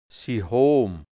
Mundart-Wörter | Mundart-Lexikon | deutsch-hianzisch | Redewendungen | Dialekt | Burgenland | Mundart-Suche: E Seite: 10